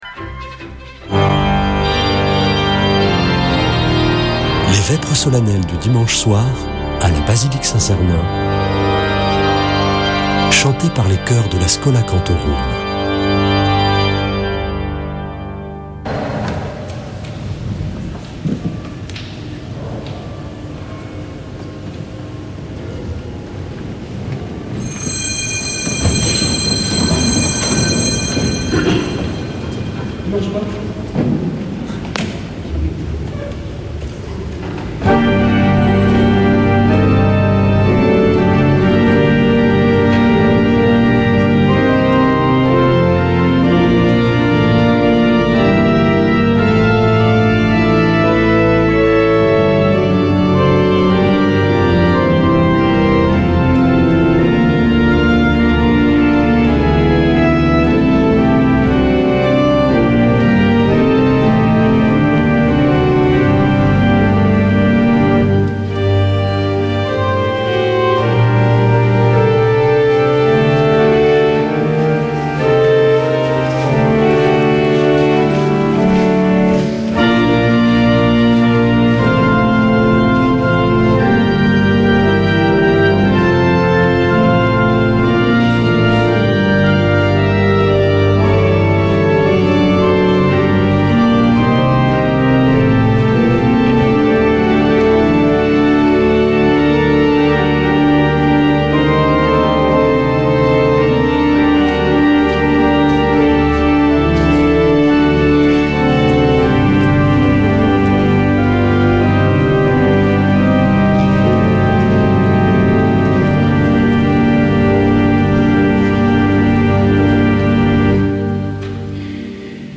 Vêpres de Saint Sernin du 17 sept.
Une émission présentée par Schola Saint Sernin Chanteurs